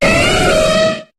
Cri de Donphan dans Pokémon HOME.